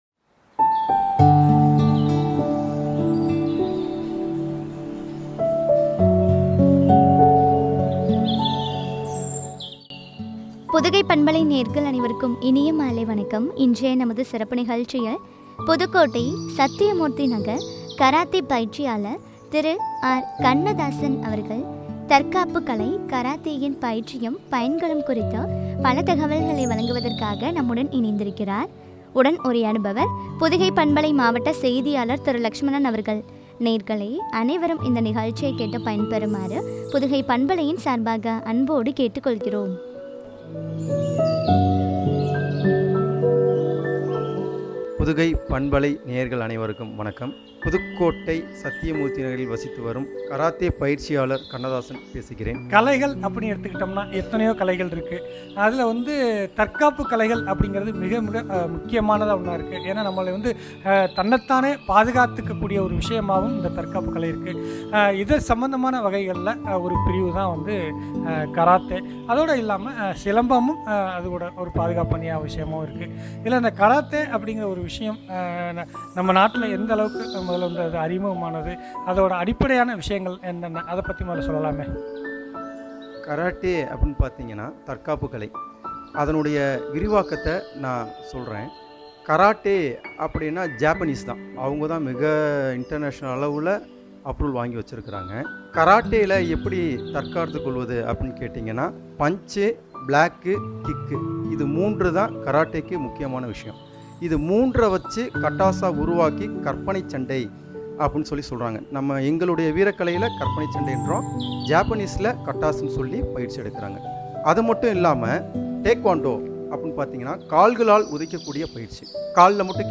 பயன்களும் பற்றிய உரையாடல்.